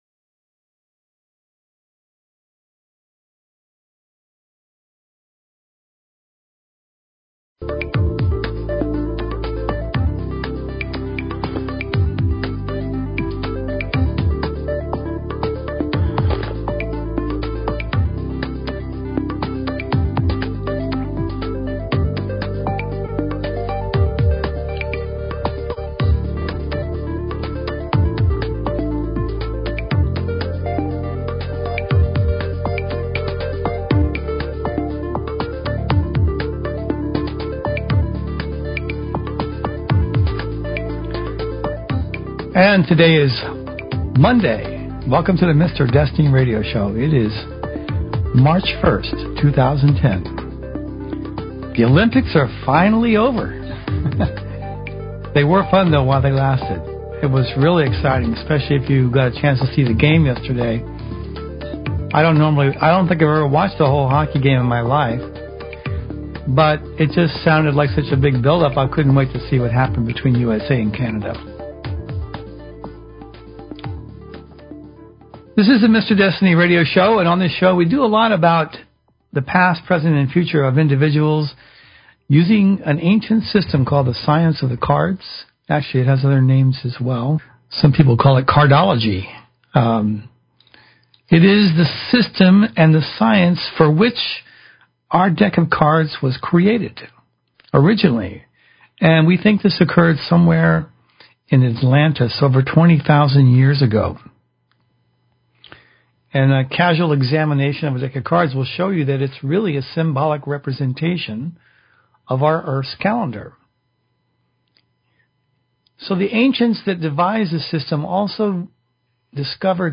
Talk Show Episode
Predictions and analysis. Guest interview or topic discussion.